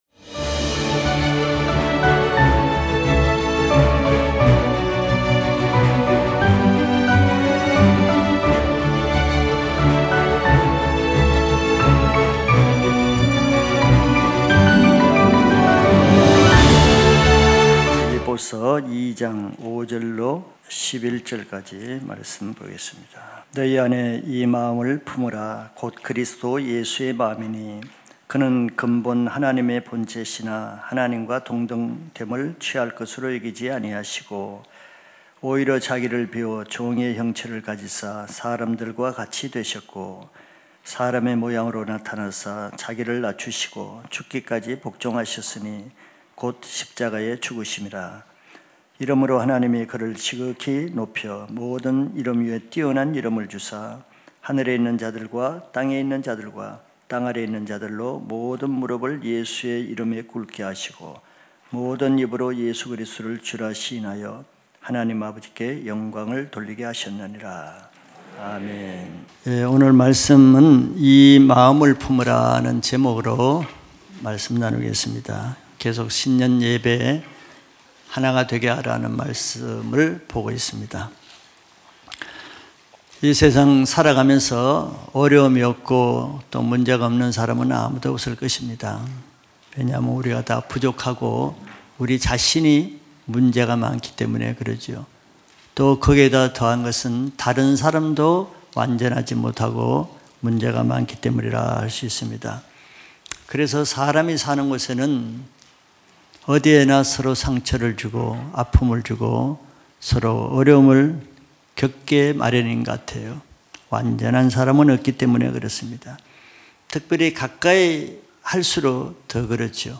주일예배말씀